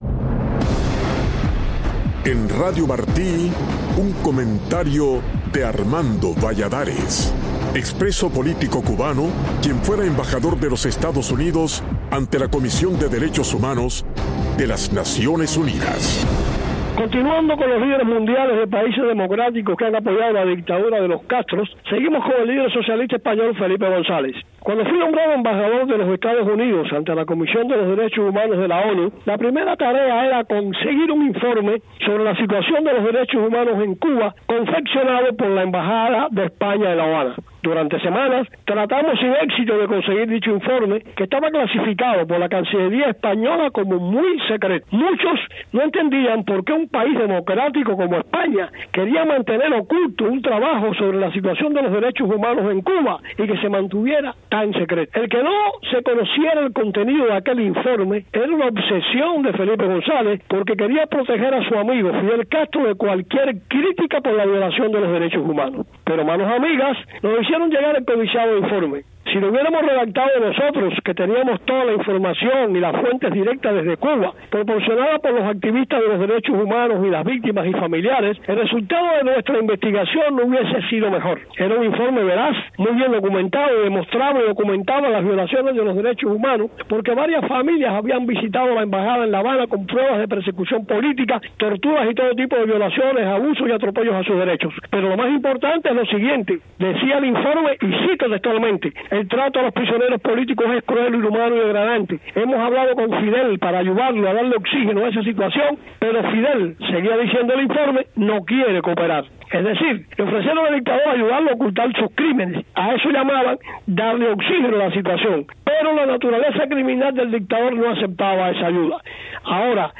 En una continuación de su comentario anterior, el exembajador de EEUU ante ONU Armando Valladares detalla la obsesión del expresidente de España por proteger de críticas a su amigo, el fallecido dictador Fidel Castro.